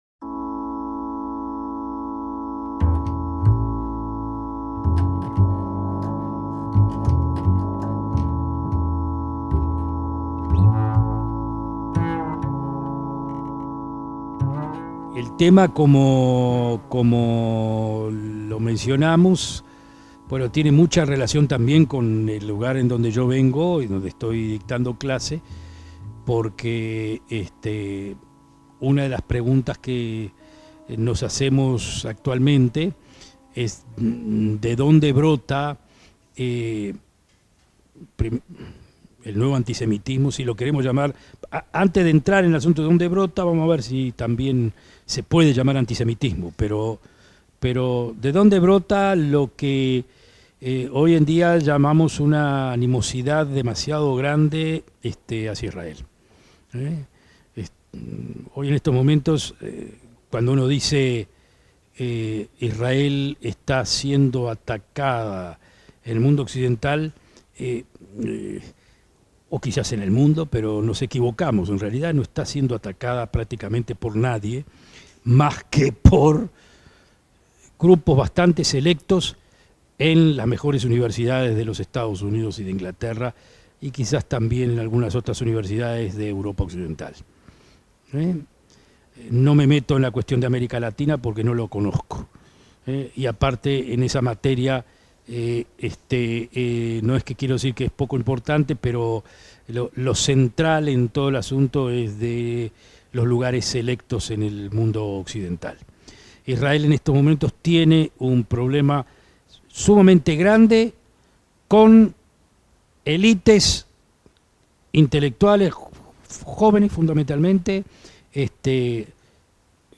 ACTOS EN DIRECTO
conferencia
La actividad tuvo lugar el jueves 27 de junio de 2019 en el campus Pocitos.